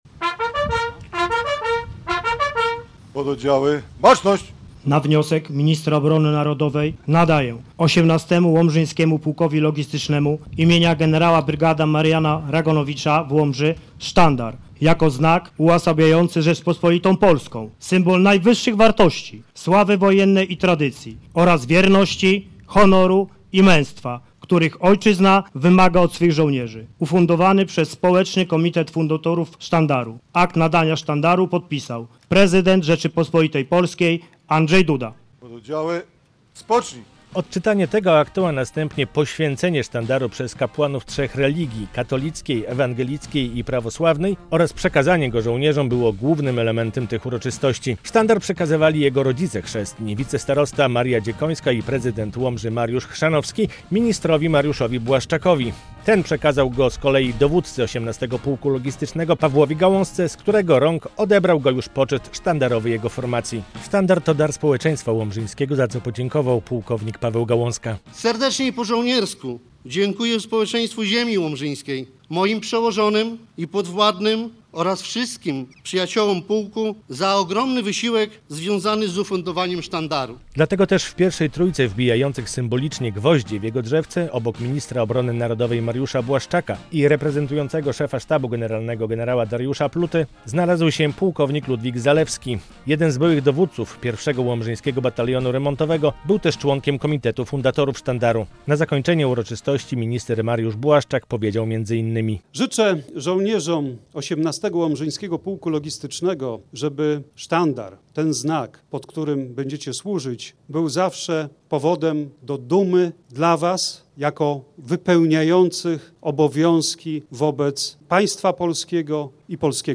Polska się zbroi, a wojsko się rozrasta po to, by nasza ojczyzna była bezpieczna i mogła się rozwijać - mówił w piątek w Łomży szef MON Mariusz Błaszczak podczas uroczystości nadania sztandaru 18. Łomżyńskiemu Pułkowi Logistycznemu.